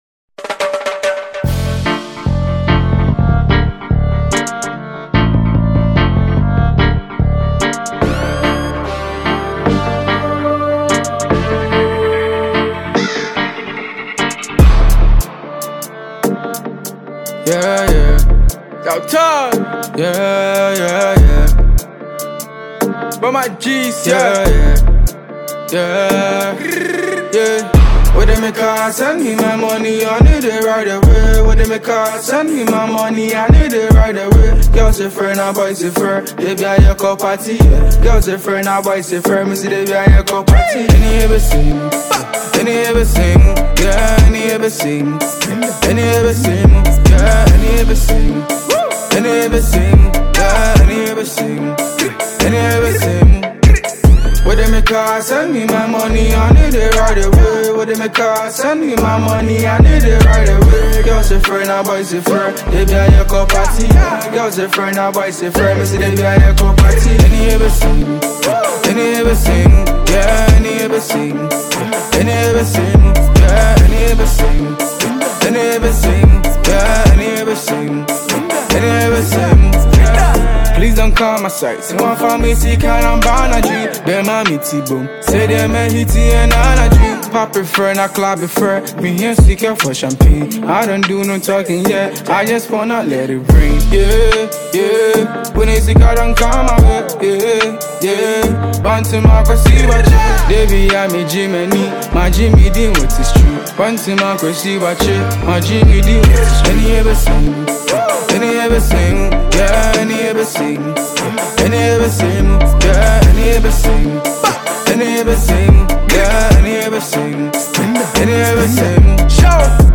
Prominent Ghanaian rapper